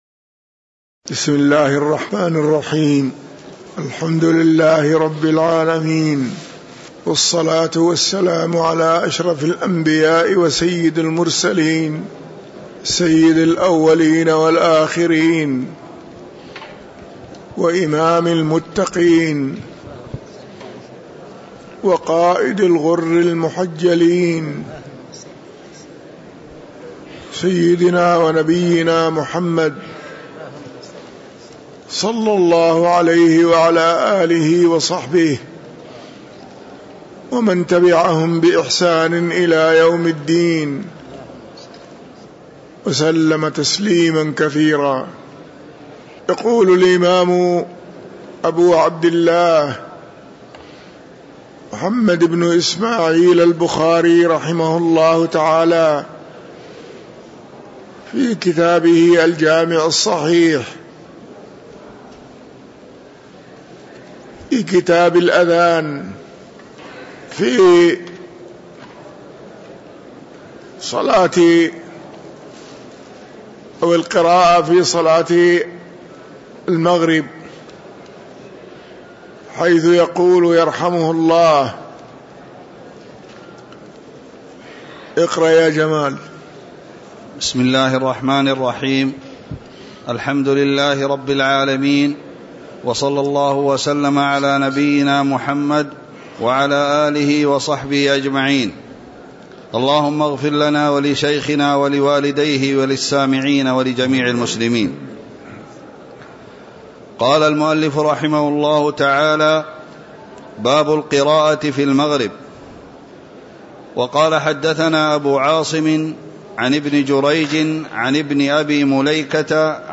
تاريخ النشر ٢٠ صفر ١٤٤٣ هـ المكان: المسجد النبوي الشيخ